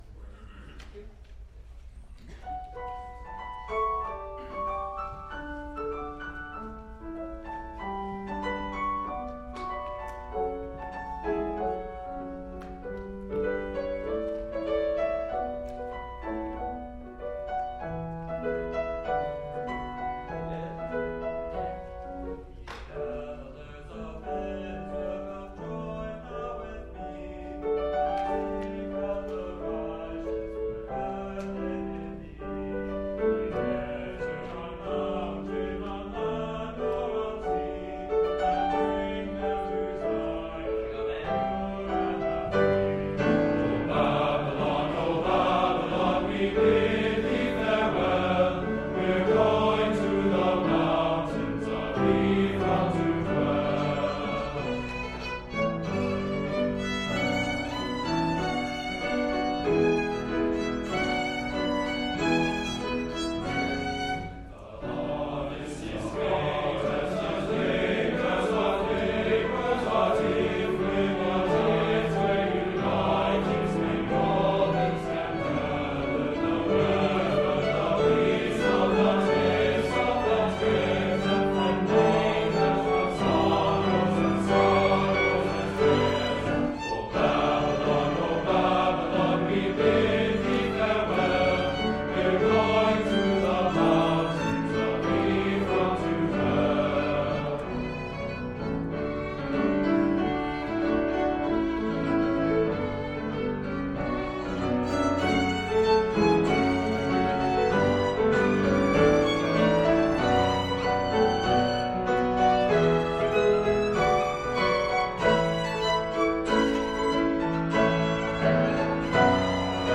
TTBB Choir, Violin, and Piano
Hymn arrangement
For church performances.